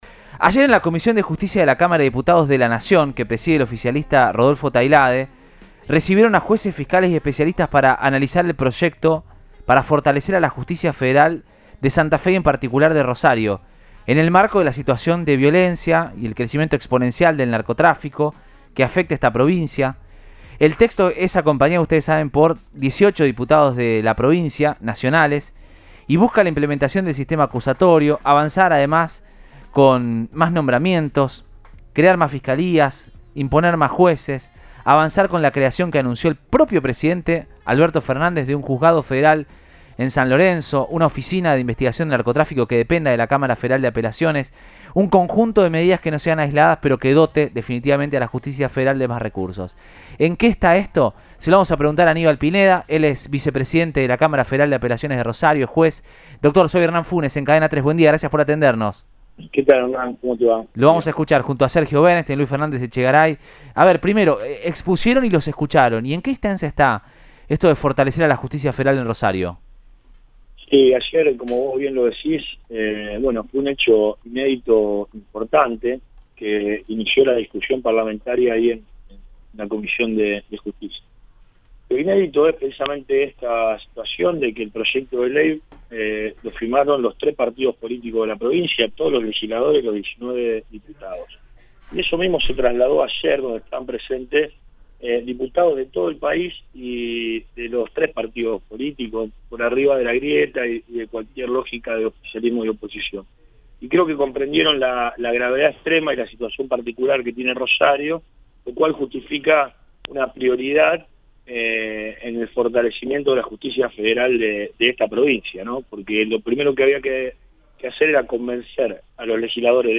“Fue un hecho inédito e importante” que “el proyecto de ley lo firmaron los tres partidos políticos más importantes de la provincia y todos los legisladores de cada una de las fuerzas”, señaló el juez Aníbal Pineda, Vicepresidente de la Cámara Federal de Apelaciones de Rosario a Radioinforme 3 de Cadena 3 Rosario.